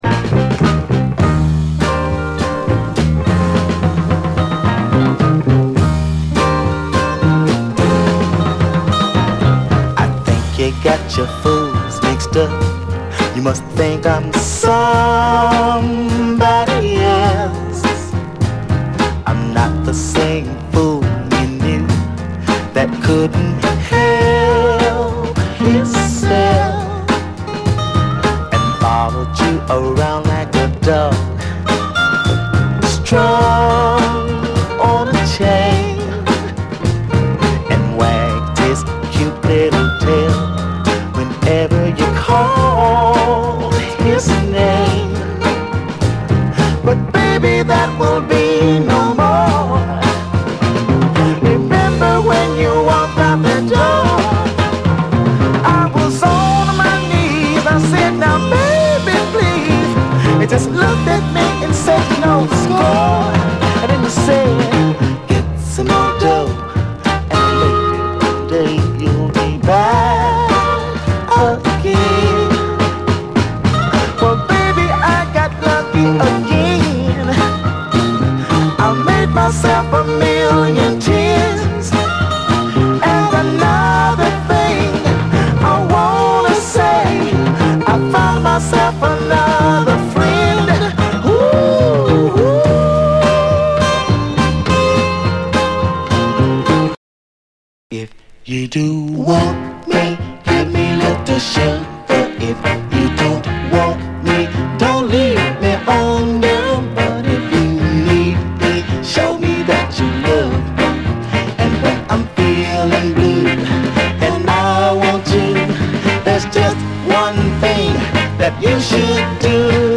60's SOUL, SOUL, 7INCH
チカーノたちにも愛され続ける、黄金の哀愁スウィート・ソウル・クラシック！
輝ける哀愁スウィート・ソウル・クラシック！